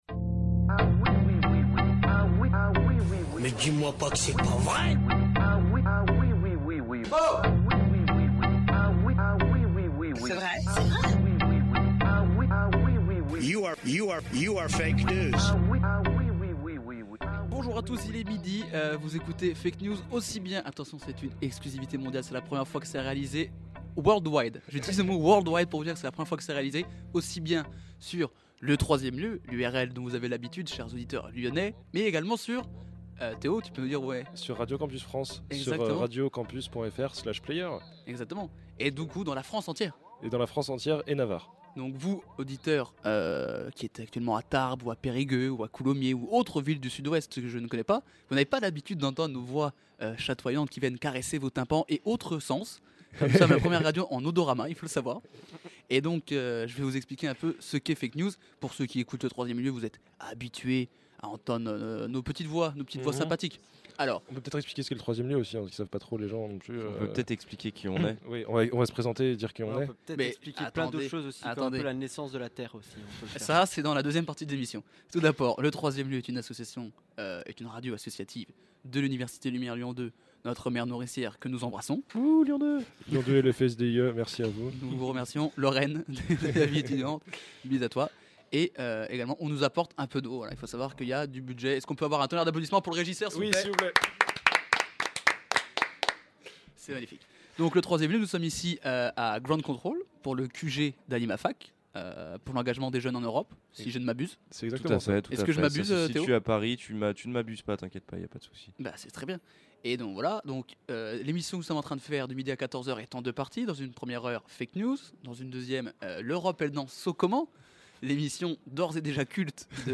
Rediffusion de l’émission réalisée dans le cadre du QG Animafac a Paris